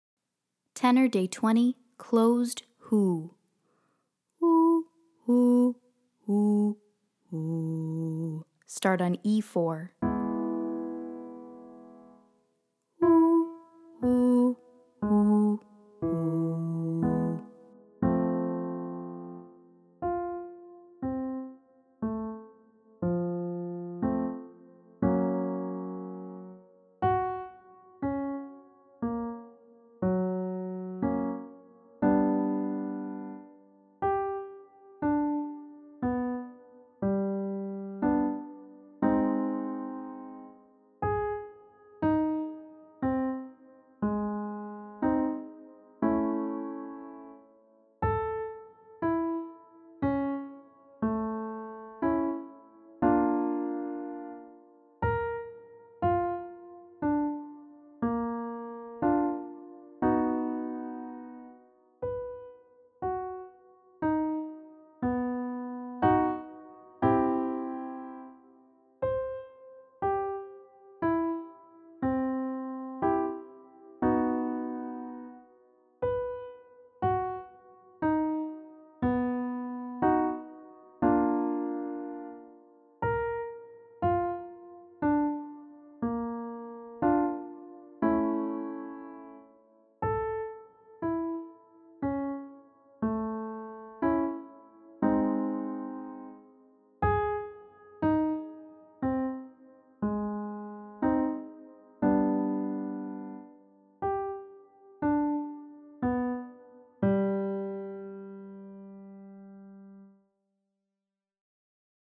Day 20 - Soprano - Closed 'HOO'